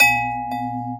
clock_chime_ticking_loop.wav